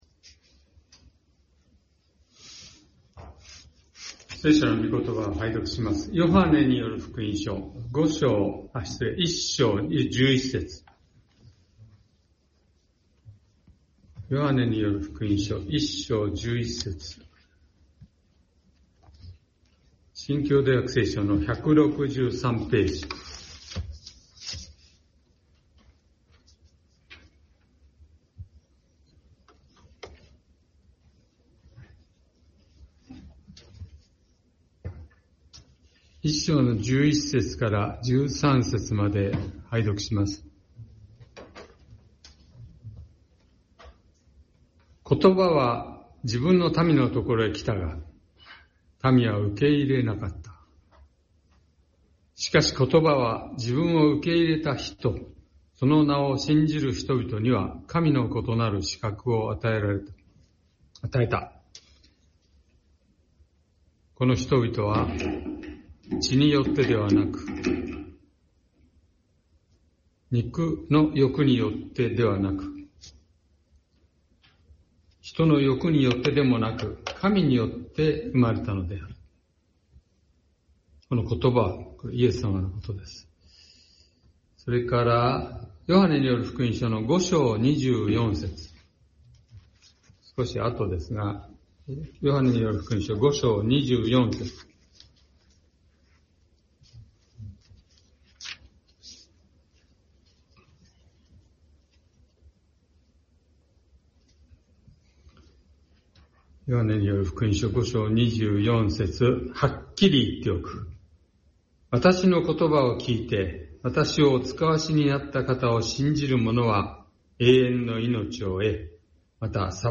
♫ いつくしみ深き 友なるイエスは 罪咎憂いを取り去り給う♪ 先週，東京集会で行われた礼拝で録音された建徳です。